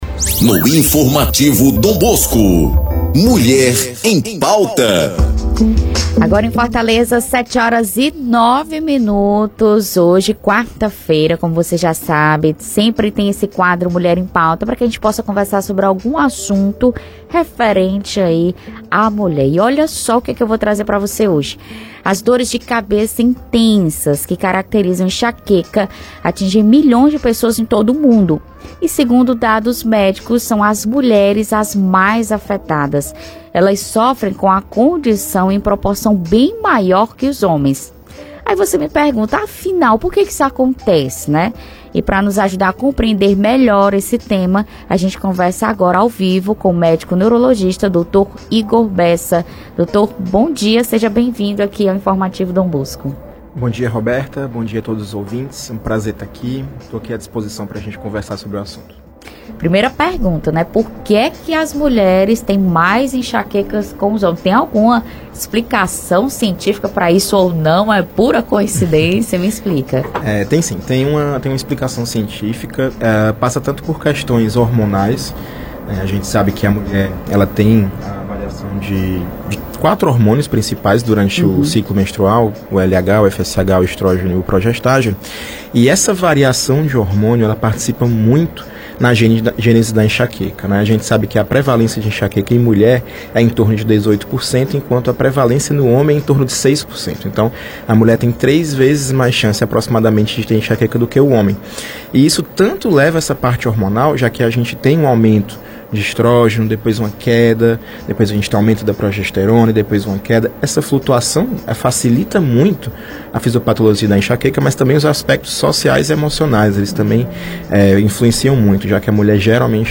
Neurologista esclarece sobre a enxaqueca ser mais comum em mulheres; confira entrevista